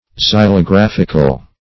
Search Result for " xylographical" : The Collaborative International Dictionary of English v.0.48: Xylographic \Xy`lo*graph"ic\, Xylographical \Xy`lo*graph"ic*al\, a. [Cf. F. xylographique.] Of or pertaining to xylography, or wood engraving.